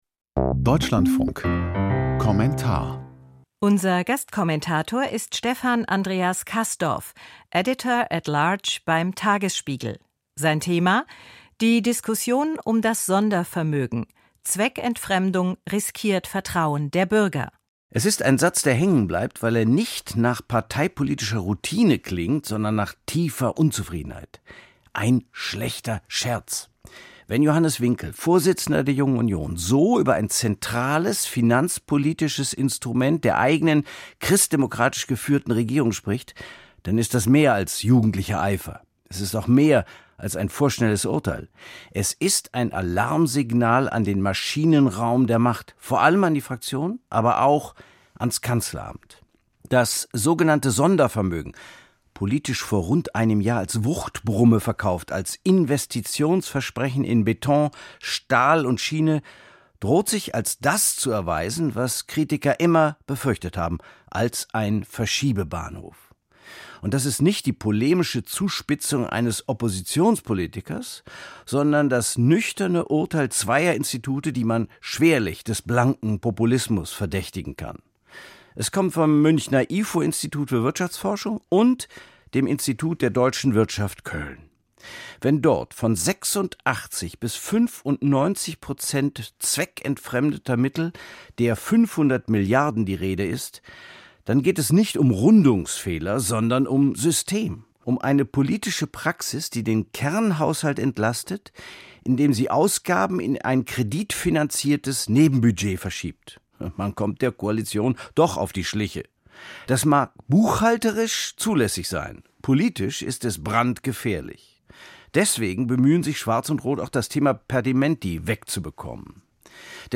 Kommentar zum Sondervermögen: Zweckentfremdung riskiert Vertrauen der Bürger